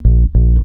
VOS BASS 2.wav